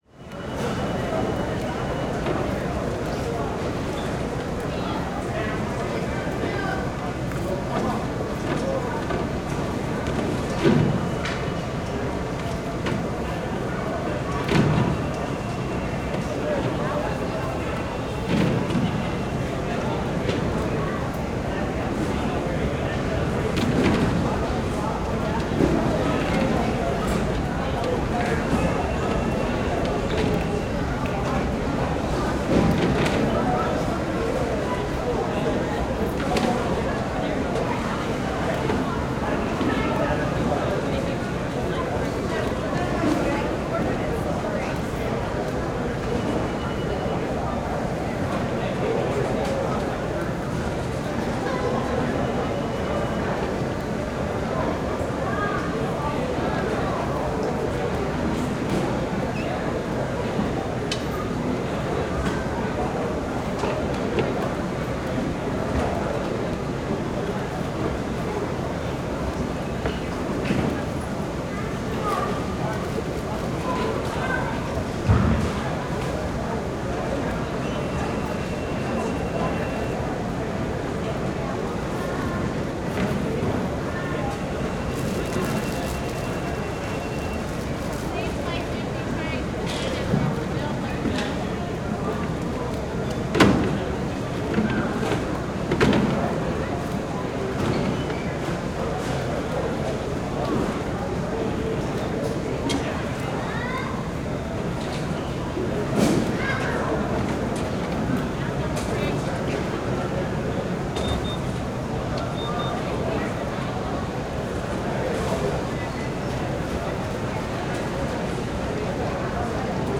Matt Script - Airplanes & Airports - Walla & Ambience
Airport_ambience_walla_terminal_luggage_security check_beeping.ogg